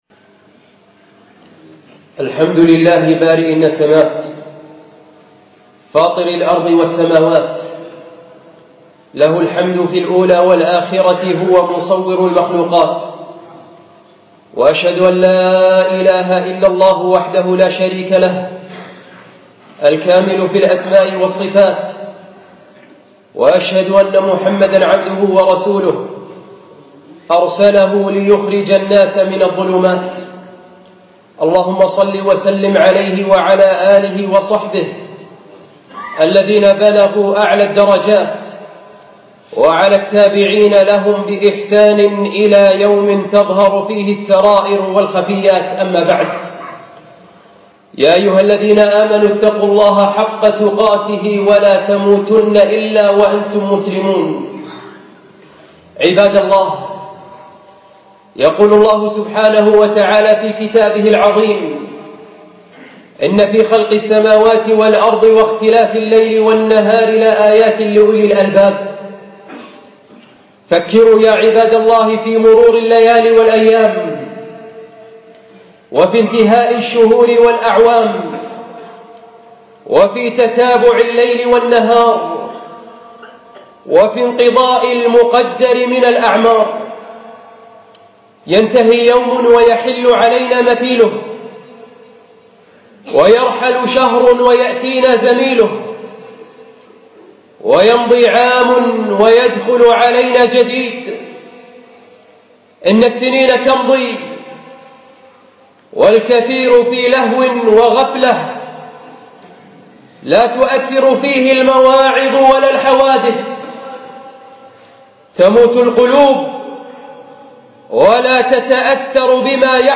خطبة بتاريخ 11 ربيع الأول 1436 الموافق 2 1 2014